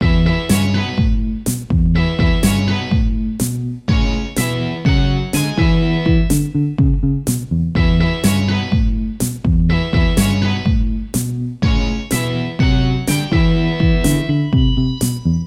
ディストーションギターが奏でる調子のいい憎めない商人のテーマソング。